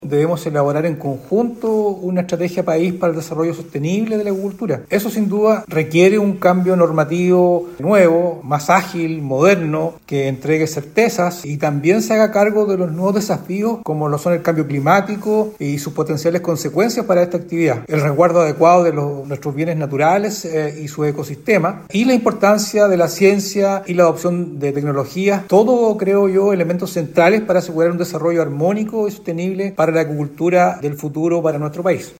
Al respecto, respondió el seremi de Economía, Luis Cárdenas, manifestando que han desarrollado con normalidad y alta convocatoria los talleres en Chiloé.
seremi-economia.mp3